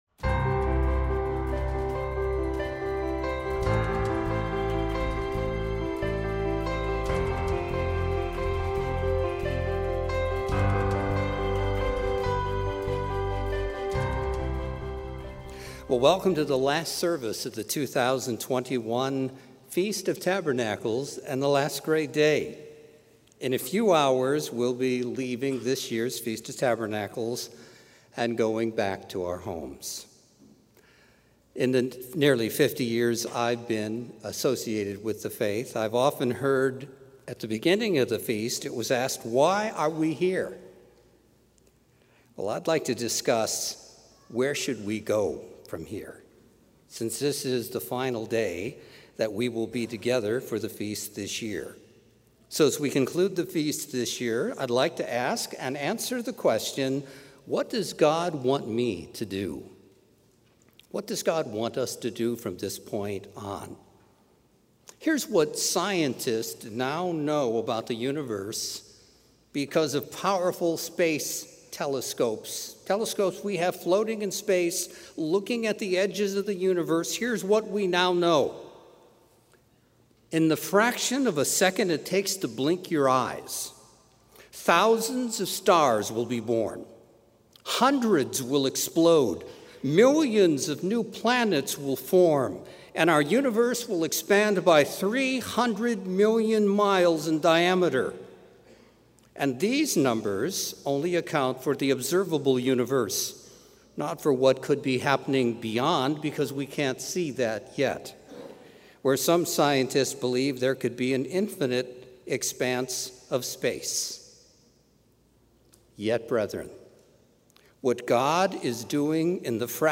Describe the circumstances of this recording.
This sermon was given at the Panama City Beach, Florida 2021 Feast site.